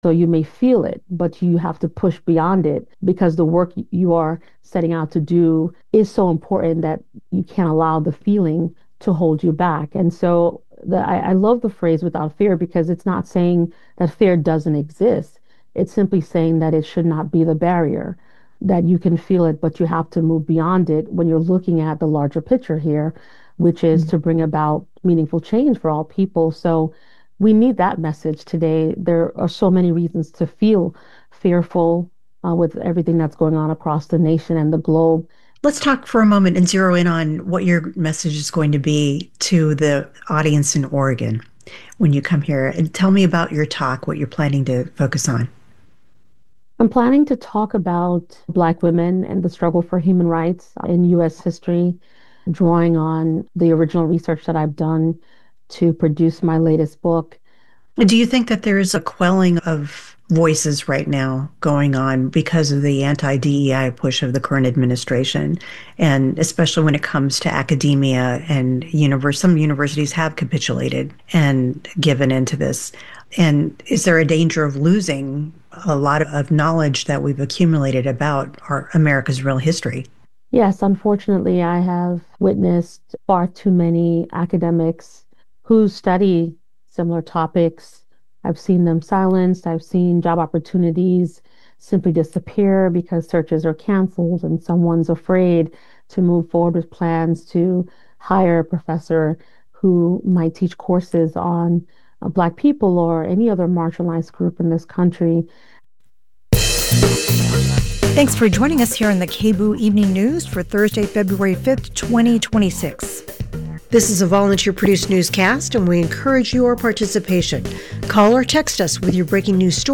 Hosted by: KBOO News Team